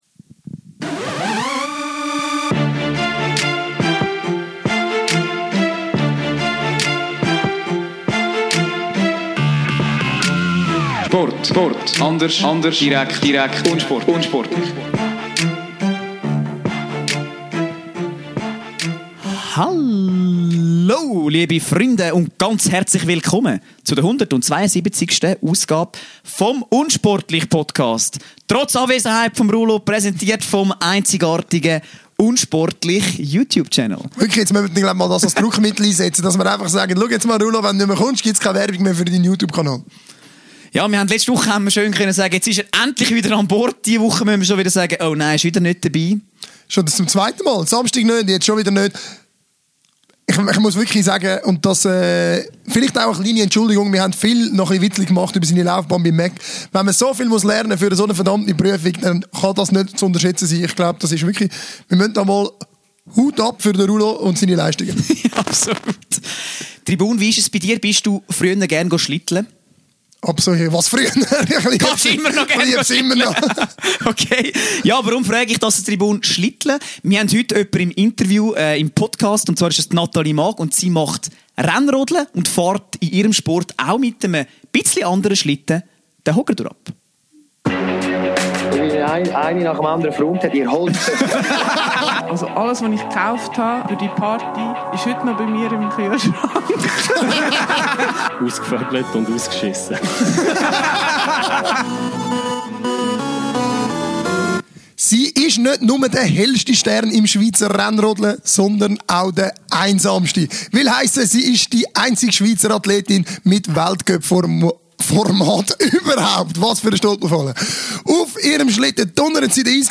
Von der aktuellen Weltcupstation in Lettland ist sie uns knapp 2 Stunden vor dem Rennen zugeschalten.